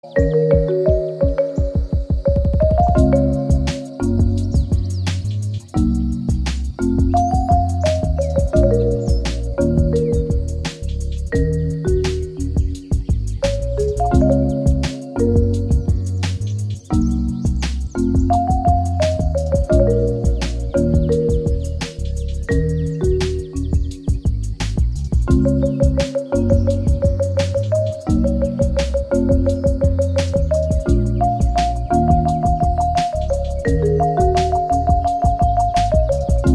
Tono para tu CELULAR